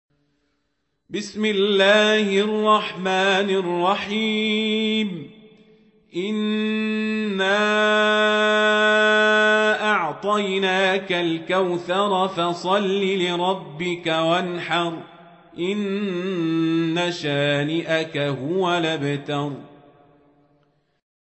سورة الكوثر | القارئ عمر القزابري